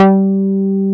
R MOOG G4F.wav